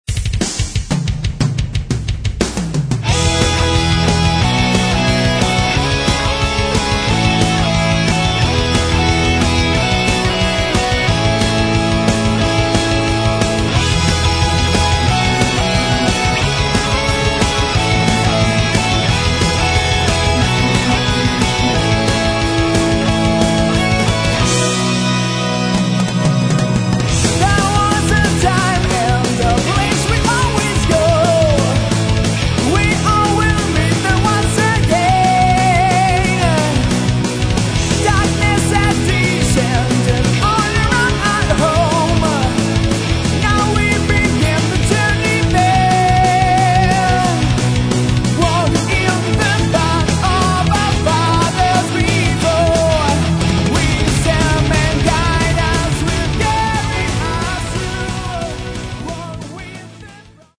Metal
Постоянные «дуэли» гитар и клавиш.